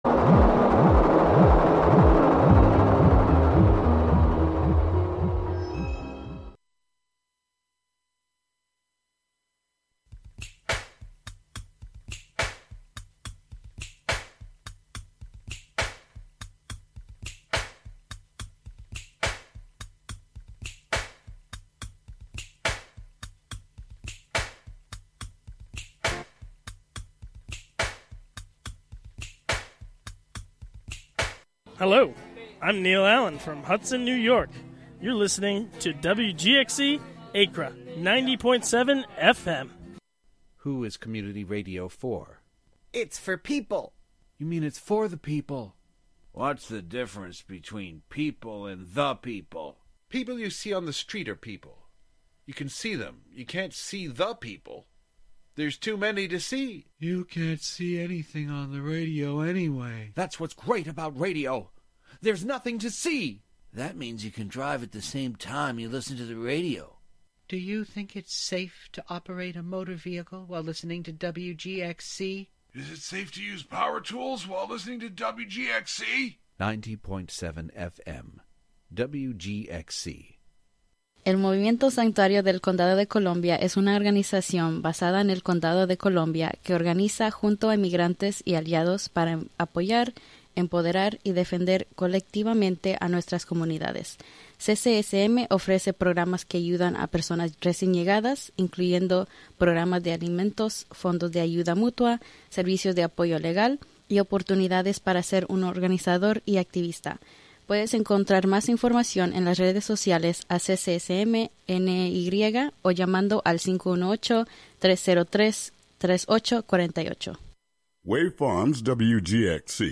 Music, talk, and schtick, just like any variety show. One difference, though, is the Democratic Socialists also confront power.